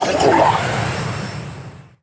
星际争霸音效-protoss-scout-pscyes01.wav